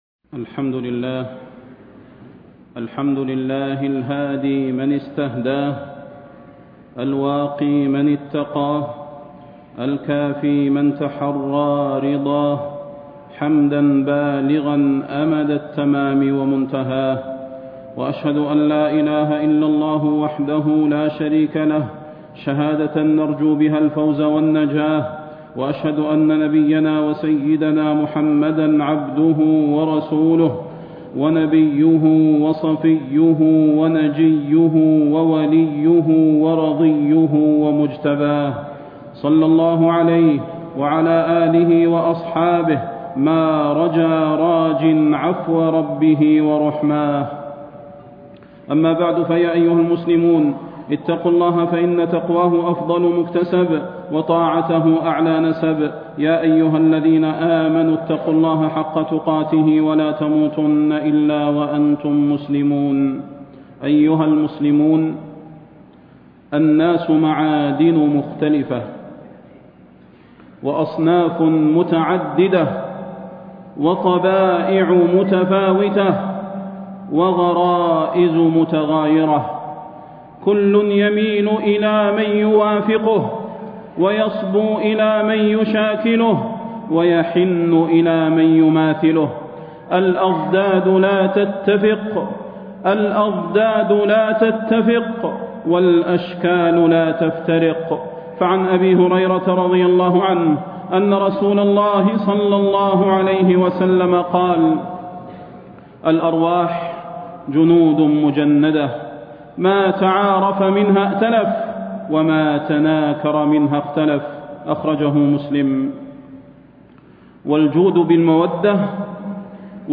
تاريخ النشر ٢٠ جمادى الأولى ١٤٣٥ هـ المكان: المسجد النبوي الشيخ: فضيلة الشيخ د. صلاح بن محمد البدير فضيلة الشيخ د. صلاح بن محمد البدير خواطر عن الصحبة الصالحة